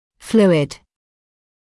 [‘fluːɪd][‘флуːид]жидкость; жидкий, текучий